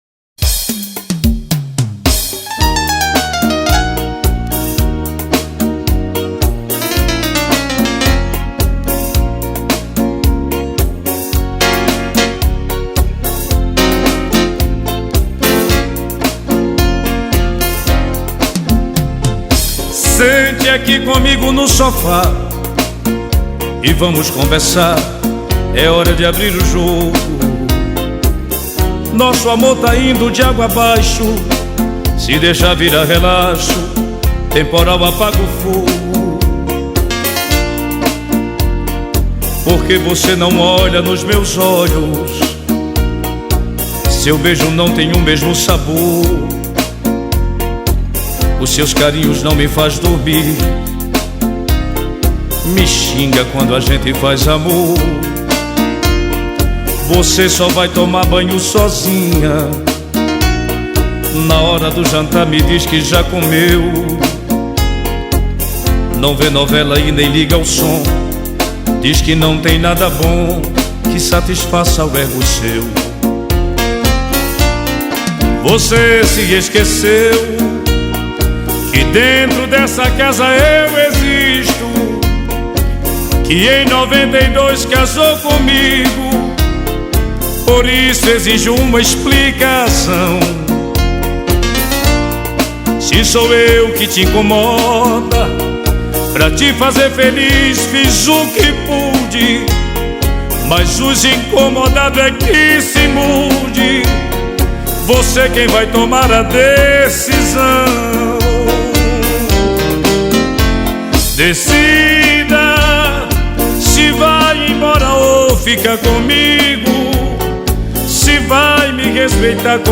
2025-01-03 17:38:45 Gênero: Arrocha Views